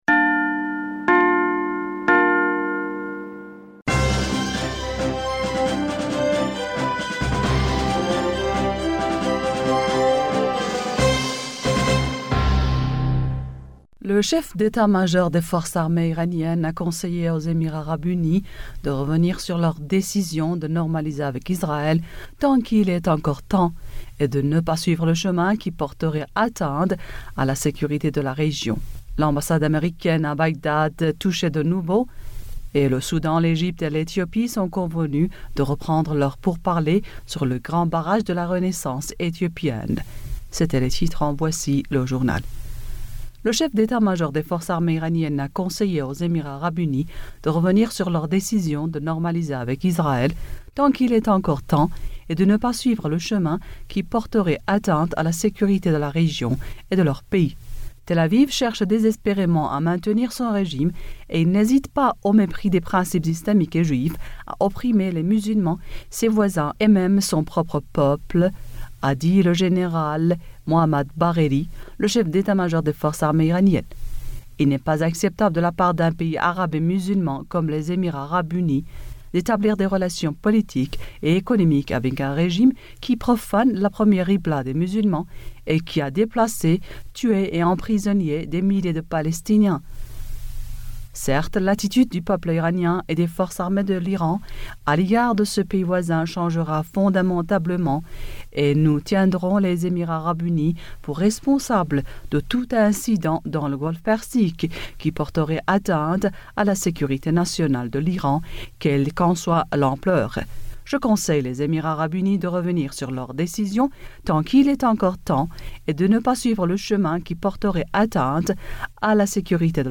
Bulletin d'information du 17 Aout 2020